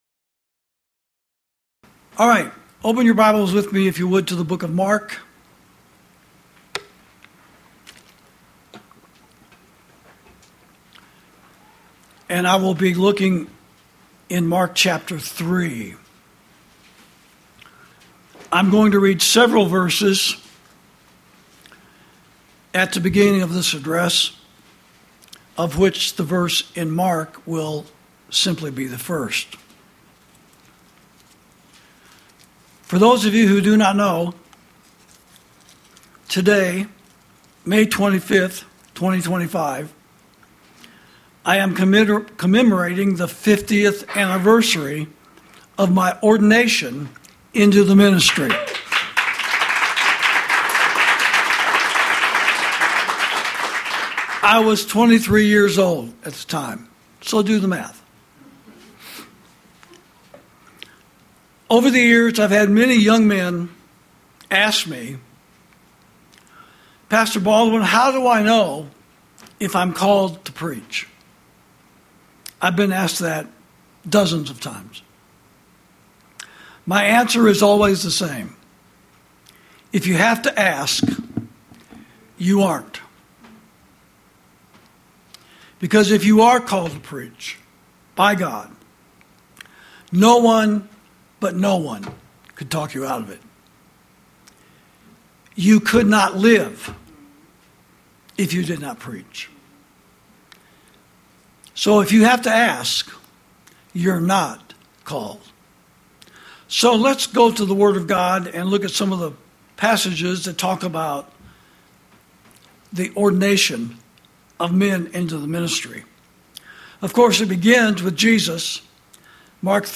Sermons > From The Moral Majority To A Moral Meltdown